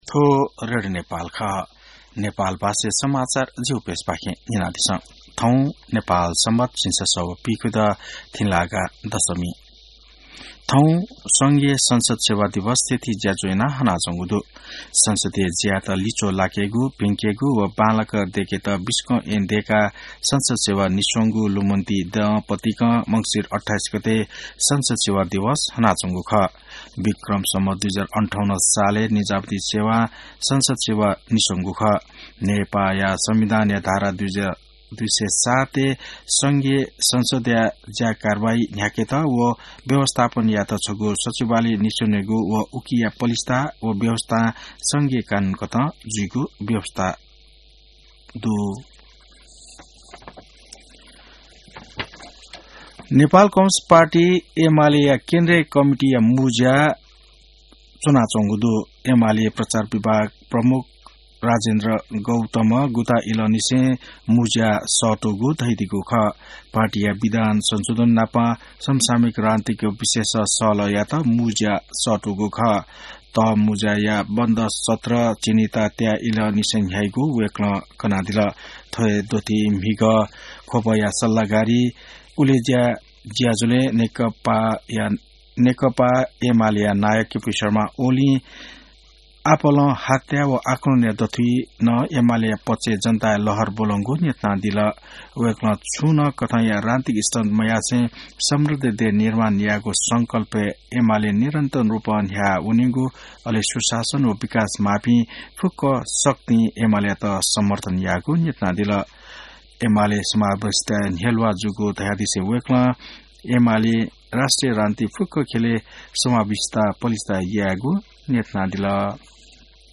नेपाल भाषामा समाचार : २८ मंसिर , २०८२